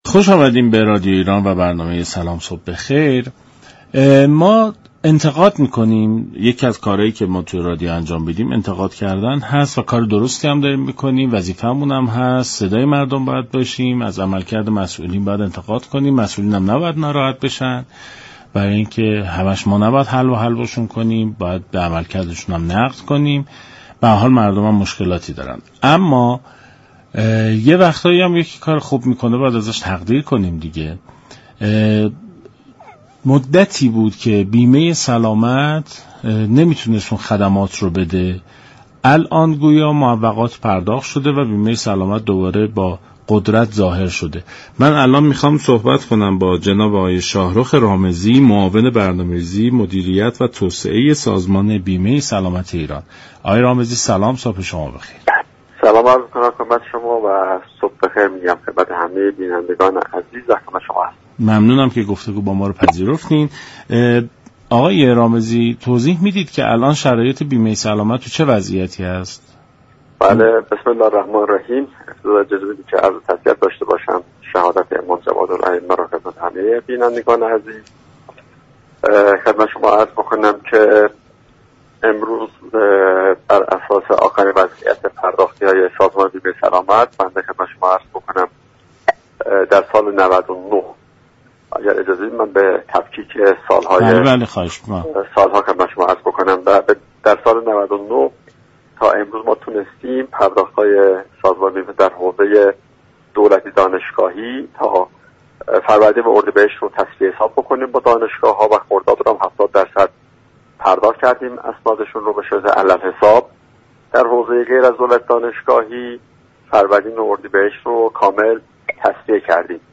به گزارش شبكه رادیویی ایران، شاهرخ رامزی معاون برنامه ریزی، مدیریت و توسعه منابع سازمان بیمه سلامت ایران در برنامه سلام صبح بخیر رادیو ایران درباره وضعیت و شرایط بیمه سلامت گفت: سازمان بیمه سلامت ایران از سال 99 تاكنون توانسته معوقات دو ماهه اول سال ( فروردین و اردیبهشت) را با دانشگاه ها تسویه حساب كند.